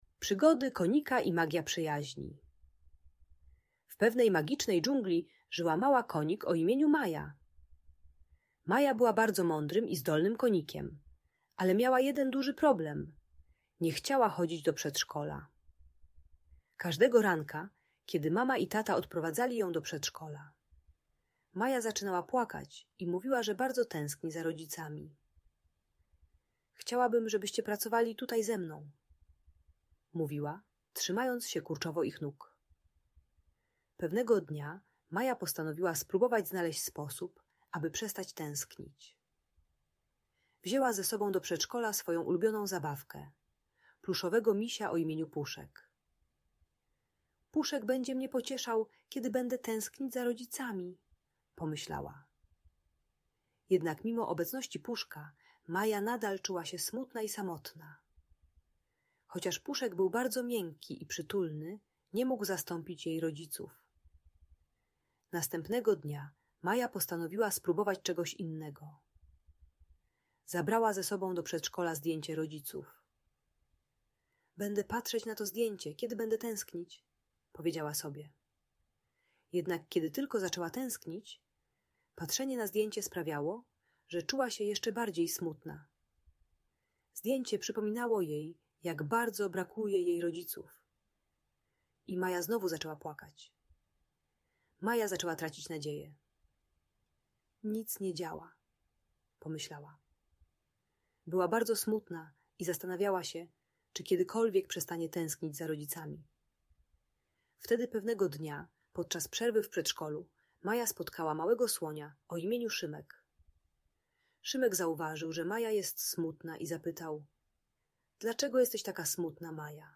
Przygody Konika Maji - Magiczna Historia o Przyjaźni - Audiobajka